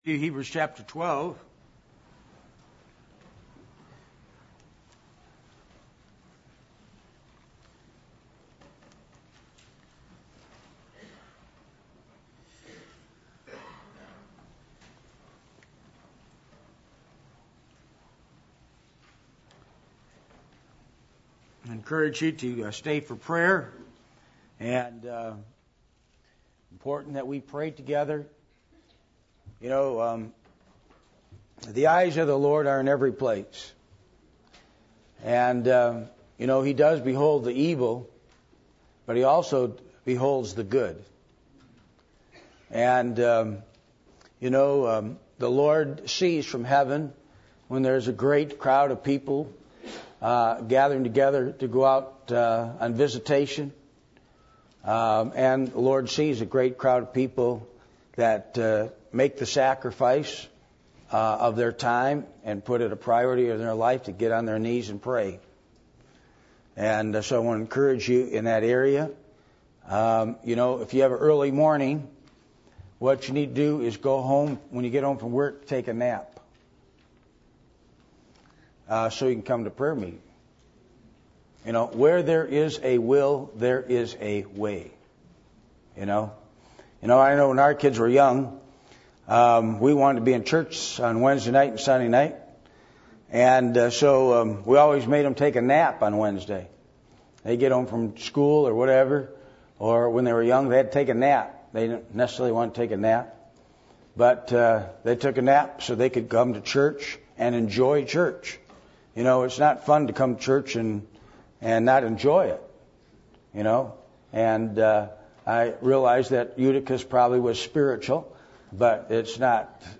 Hebrews 12:13-14 Service Type: Midweek Meeting %todo_render% « Discouragement Biblical Principles Of Finances